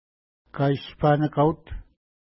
Pronunciation: ka:i:spa:nəka:wt